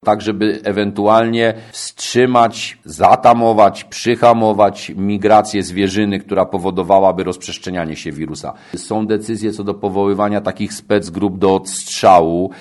Mówi wicestarosta mielecki Andrzej Bryła: